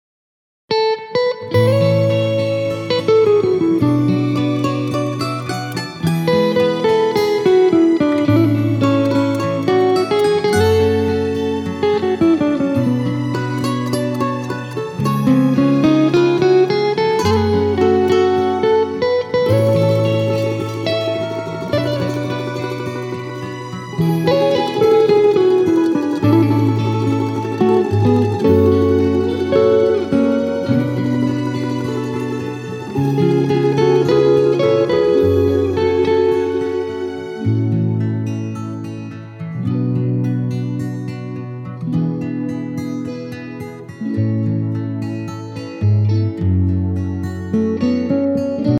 Гитара с мандолиной
На прошлой неделе делал аранжировку в которой я использовал мандолину.